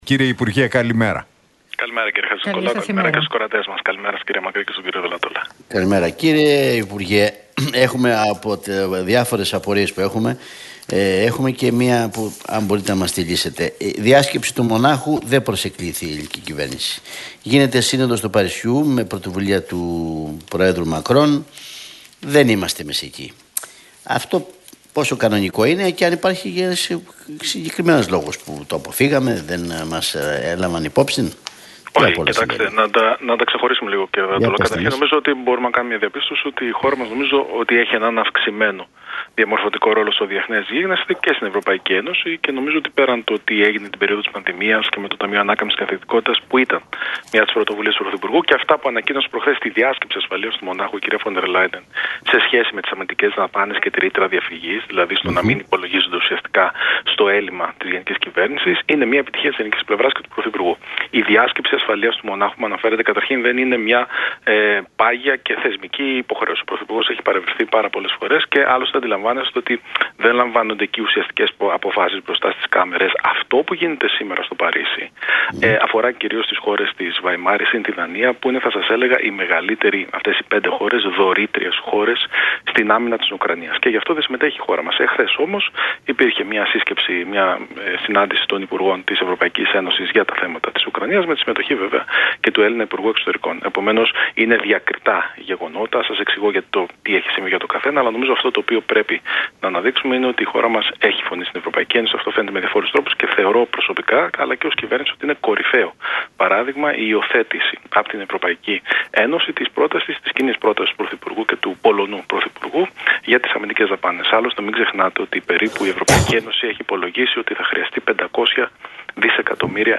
Μία εφ' όλης της ύλης συνέντευξης παραχώρησε ο υφυπουργός παρά τω Πρωθυπουργώ, Θανάσης Κοντογεώργης στον Realfm 97,8 και τους Νίκο Χατζηνικολάου